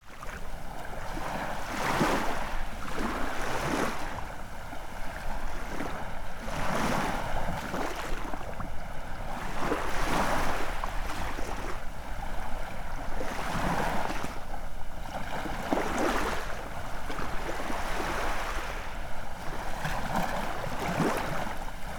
Нежные волны океана ласкают пляж